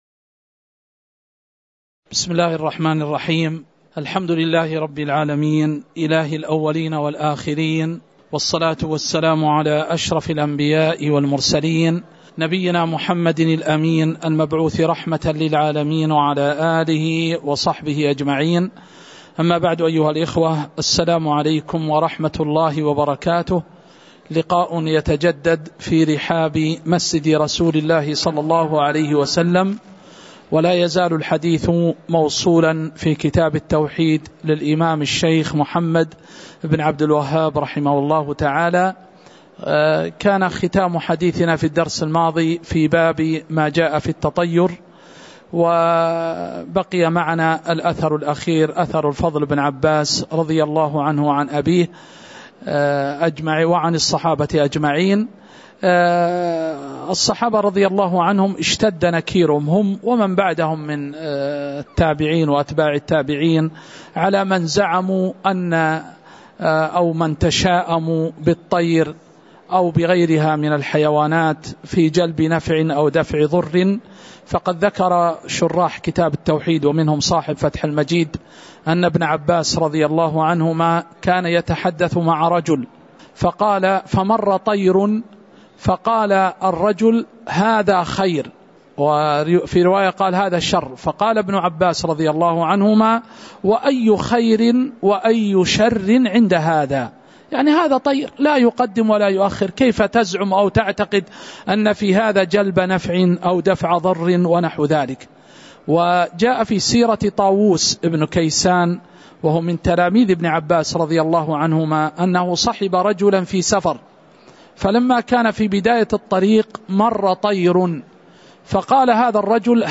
تاريخ النشر ١٩ شوال ١٤٤٠ هـ المكان: المسجد النبوي الشيخ